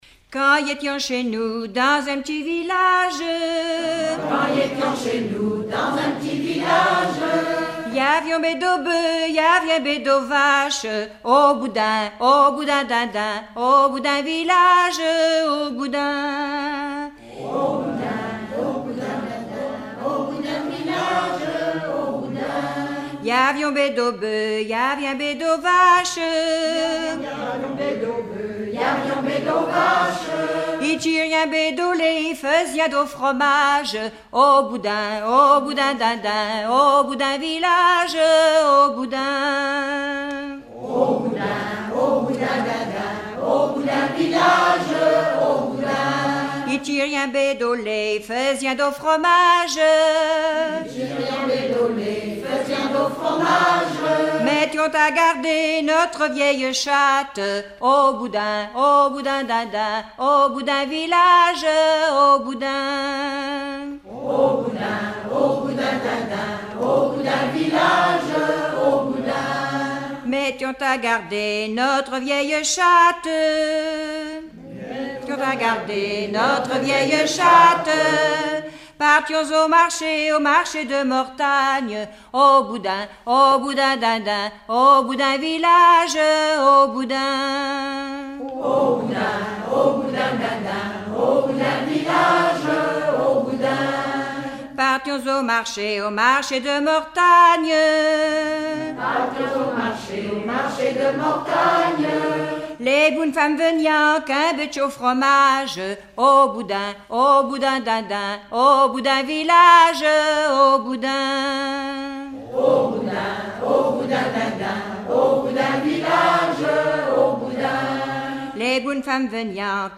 Genre laisse
Regroupement de chanteurs du canton
Pièce musicale inédite